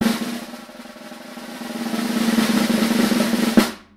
_Trommelwirbel_ Download
trommelwirbel.mp3